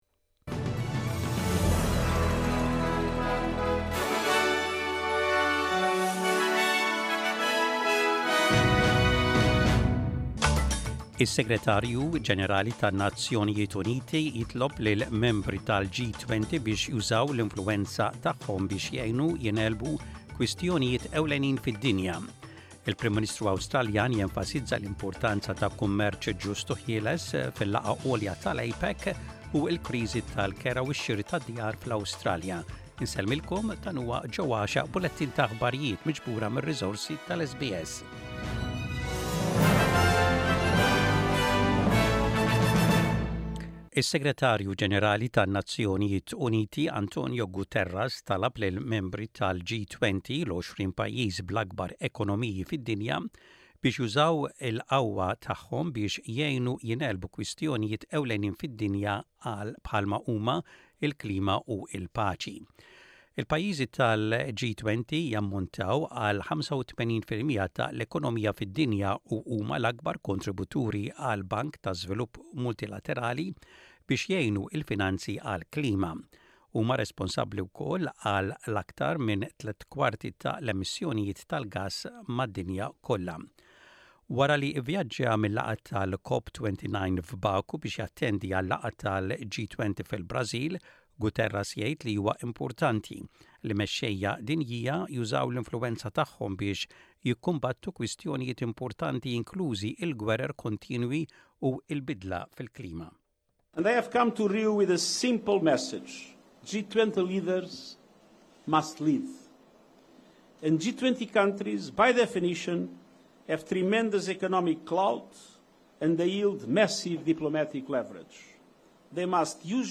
SBS Radio | Aħbarijiet bil-Malti: 19.11.24